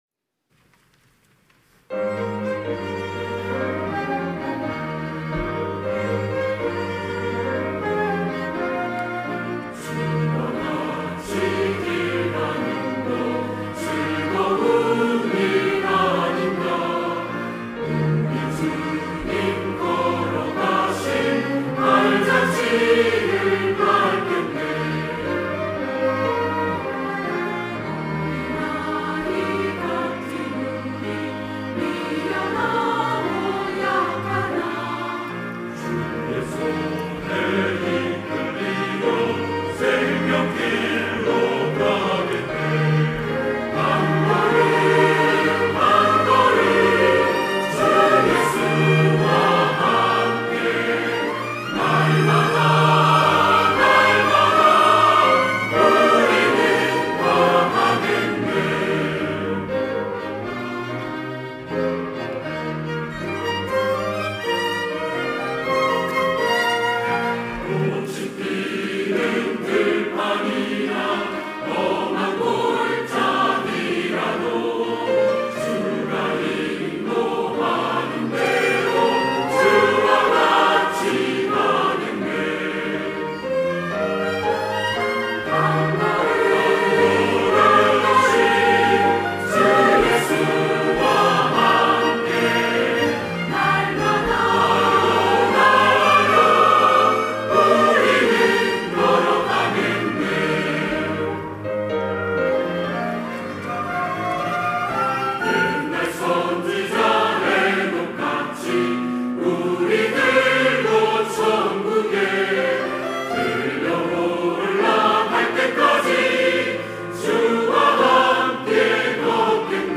할렐루야(주일2부) - 주와 같이 길 가는 것
찬양대